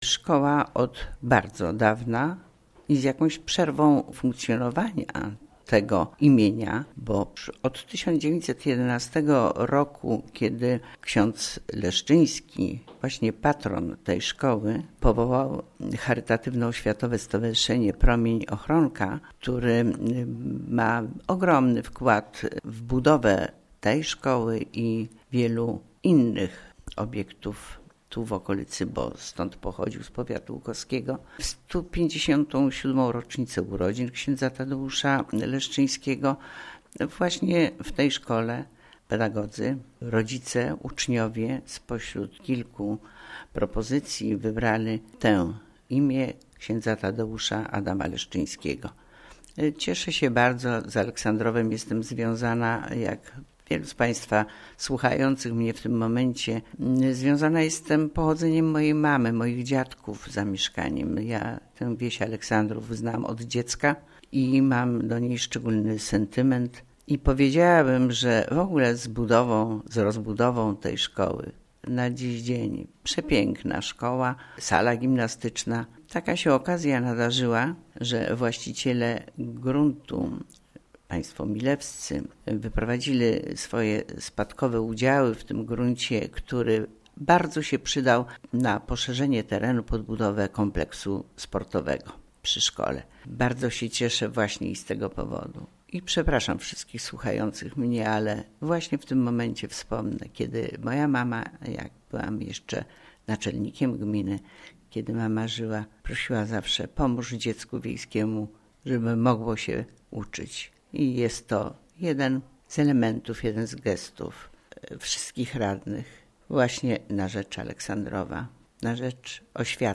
(komentarz Wójta Gminy Łuków - Pani Kazimiery Goławskiej) oraz zatwierdzili wzór sztandaru.